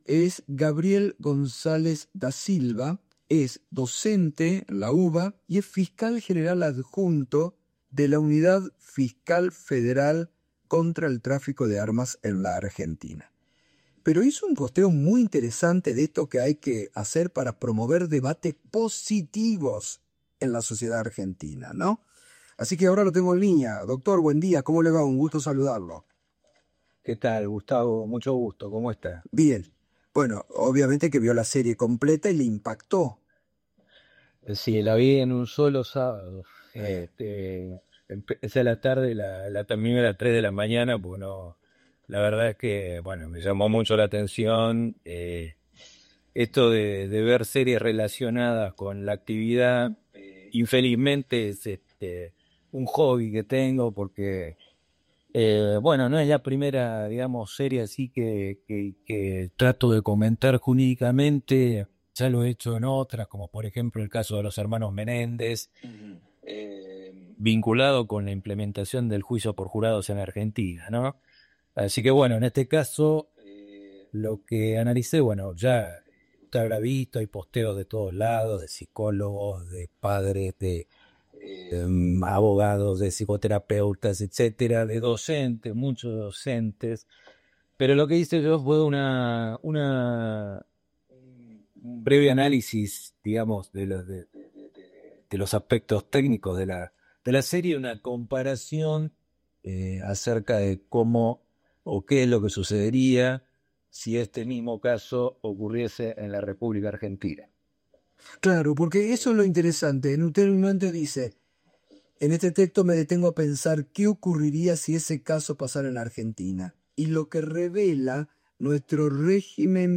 Entrevista en «Mañana Silvestre» — Radio 10, AM 710 con motivo de las publicaciones que efectuara comparando la serie “Adolescencia” de Netflix con el sistema penal argentino